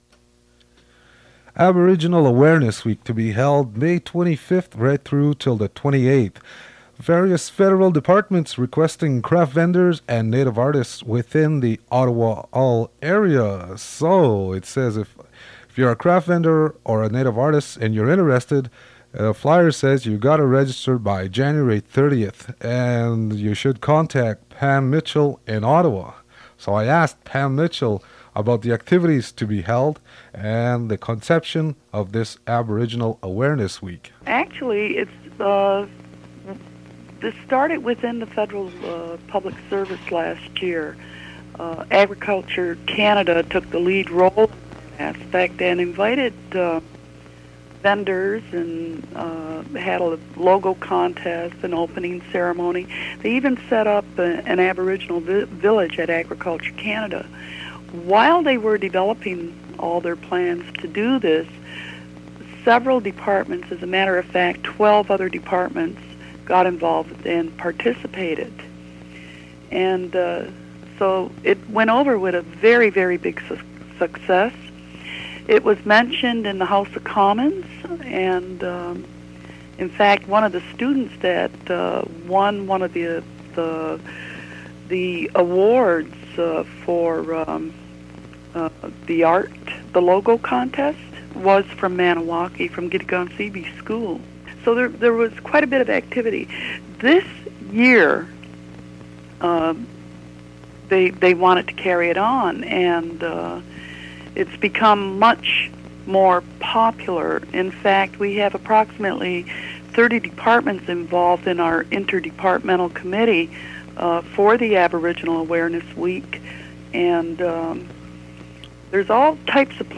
Fait partie de Aboriginal Awareness Week announcement